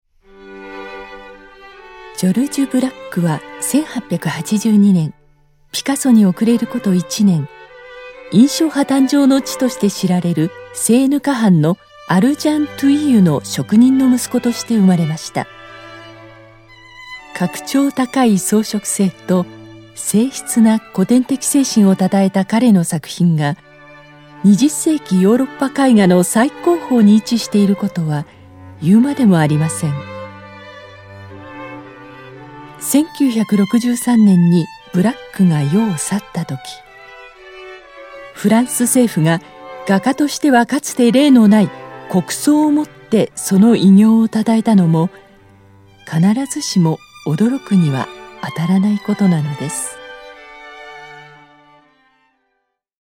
音声サンプル
落ち着いた喋りと優しく穏やかな声で、安定感のあるメッセージをお届けします。